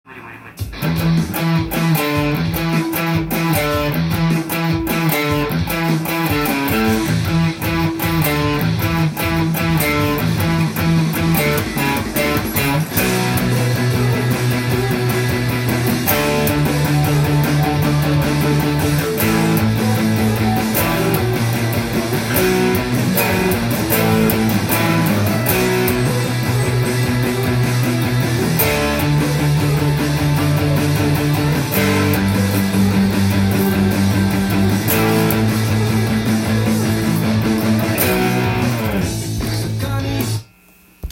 音源に合わせて譜面通り弾いてみました
エレキギターのディストーションサウンドが軽快に聞こえる曲です。
ロックなリフから始まっています。
裏拍を意識したイントロなので、裏を把握することが
更にコードが変わるタイミングもやはり裏拍です。